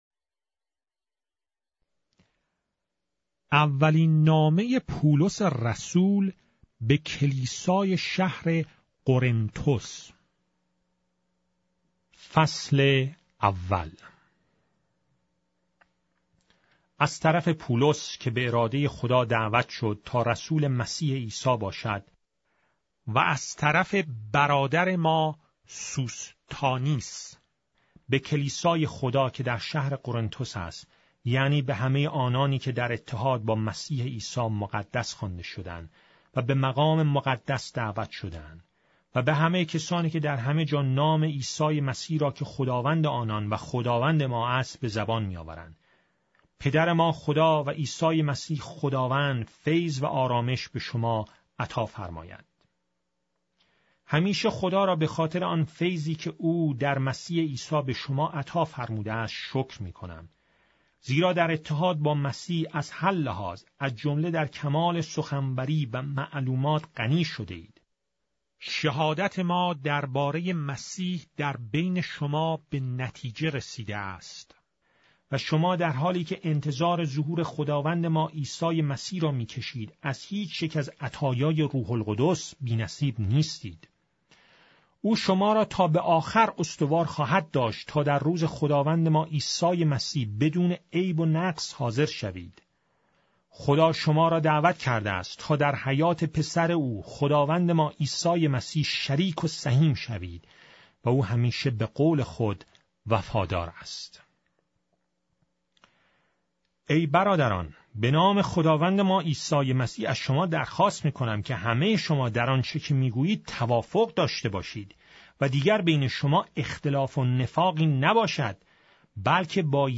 فصل از کتاب مقدس به زبان فارسی - با داستان های صوتی - 1 Corinthians, chapter 1 of the Holy Bible in Persian